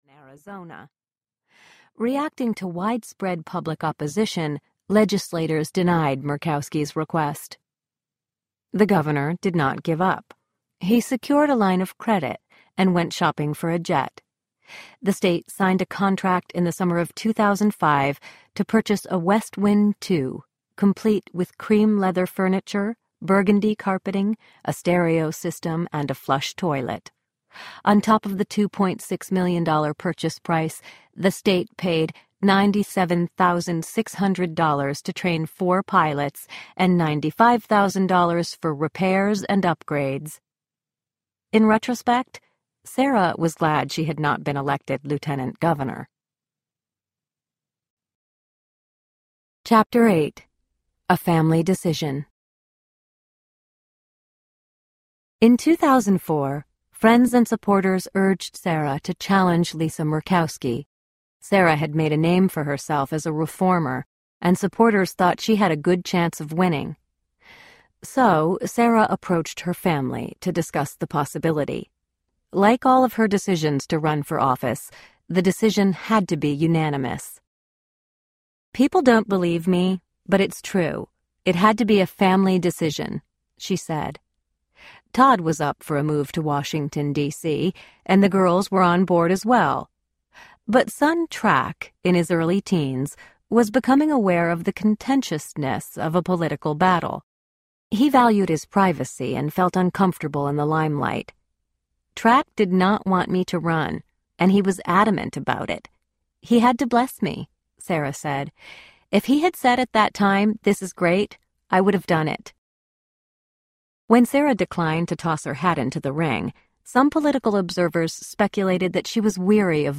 Sarah: How a Hockey Mom Turned Alaska’s Political Establishment Audiobook
3 Hrs. – Unabridged